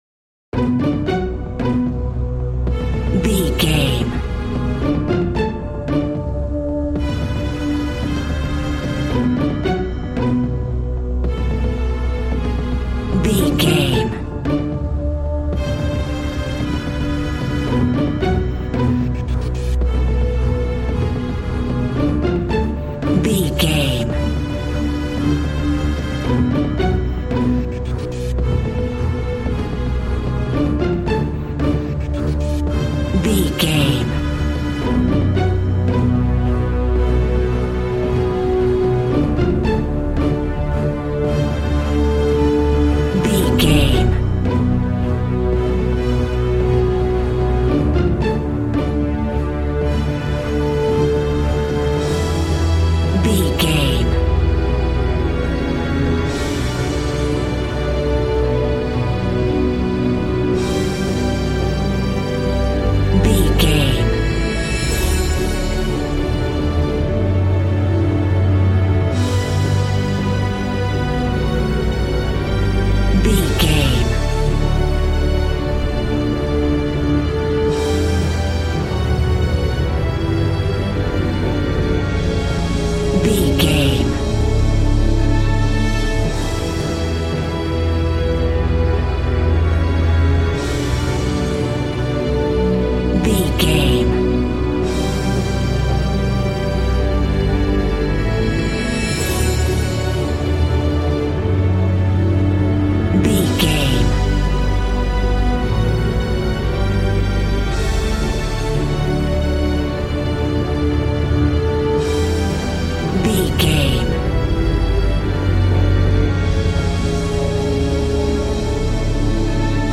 Epic / Action
Fast paced
In-crescendo
Uplifting
Aeolian/Minor
strings
brass
percussion
synthesiser